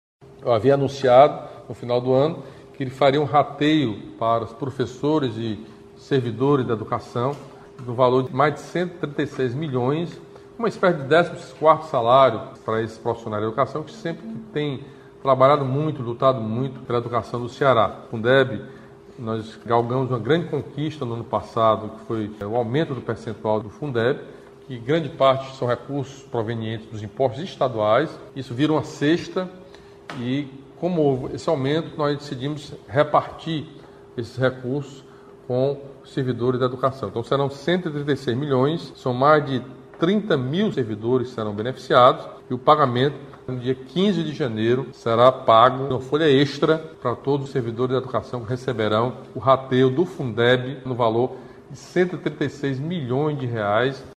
No primeiro bate-papo virtual com a população em 2022, realizado nesta terça-feira (4), o governador Camilo Santana anunciou o rateio proporcional de R$ 136 milhões provenientes do Fundo de Manutenção e Desenvolvimento da Educação Básica e de Valorização dos Profissionais da Educação (Fundeb) para cerca de 30 mil profissionais da Educação do Ceará, a ser pago em 15 de janeiro.